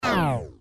slowdown.mp3